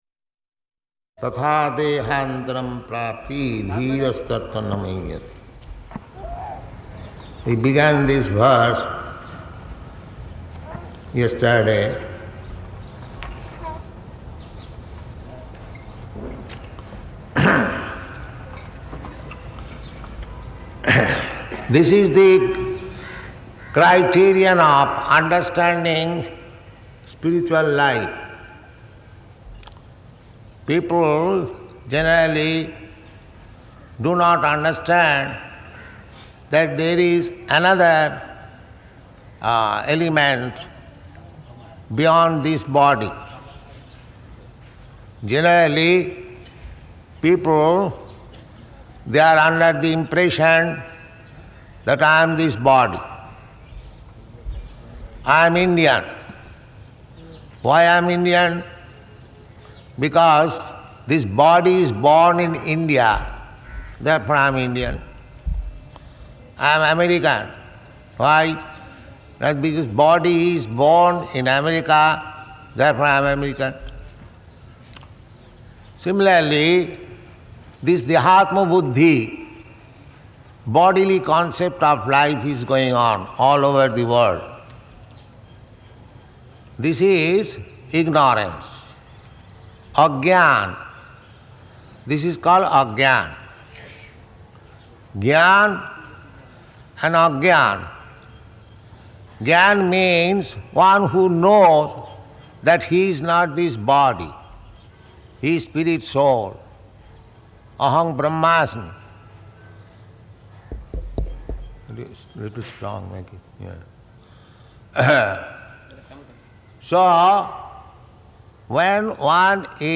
Type: Bhagavad-gita
Location: Hyderabad